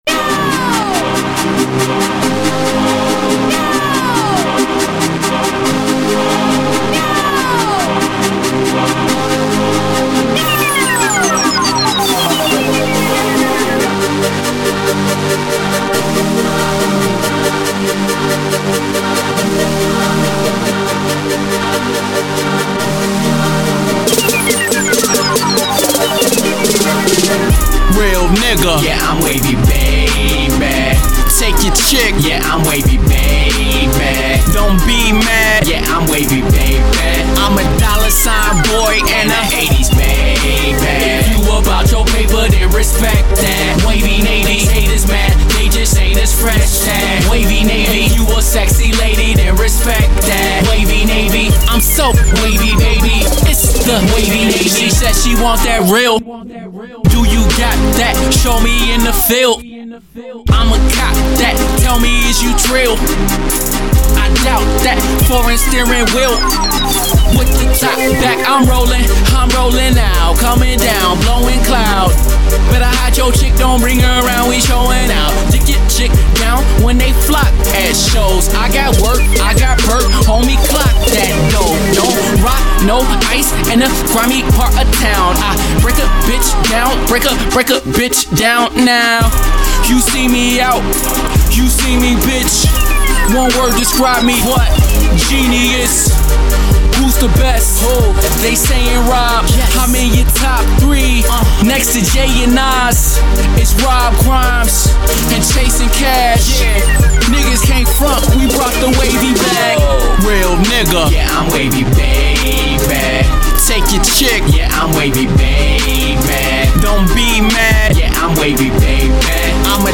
a fun, energetic club banger